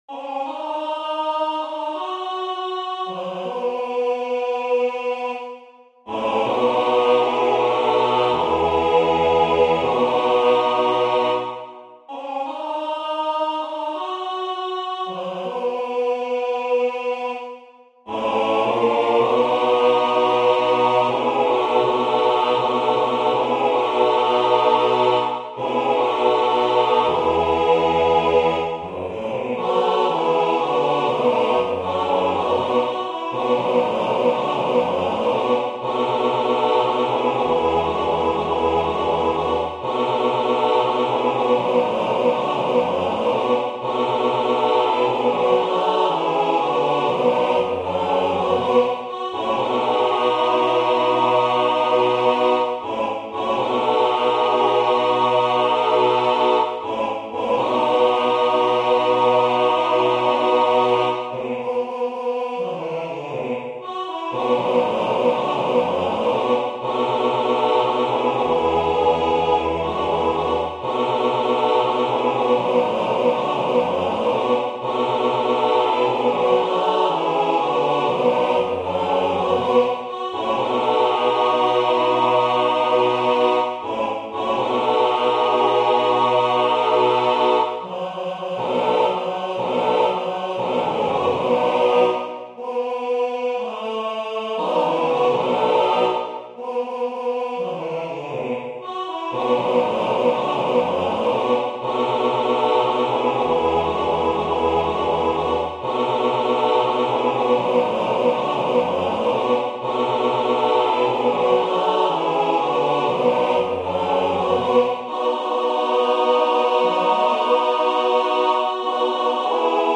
Arrangement pour chœur
Genre : gospel
anglais Voix : SAAB Difficulté